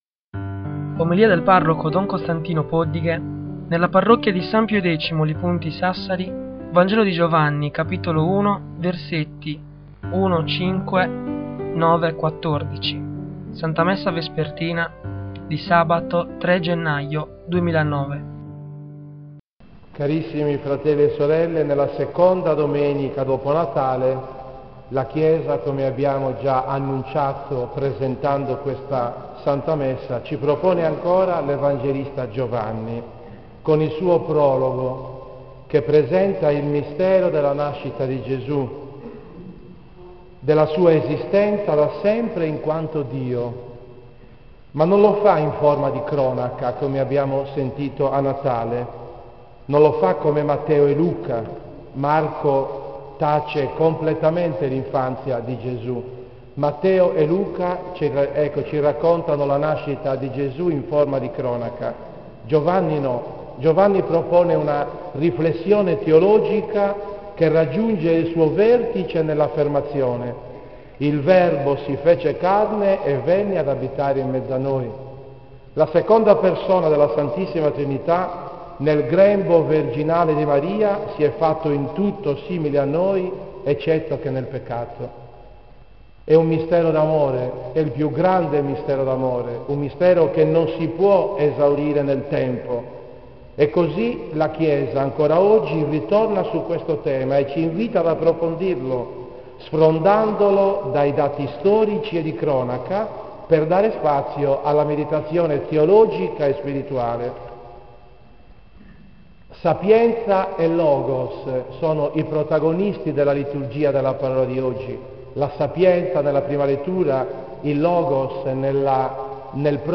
In questa sezione puoi ascoltare le omelie del parroco sul Vangelo della domenica indicata.
OMELIE TEMPO DI NATALE 2009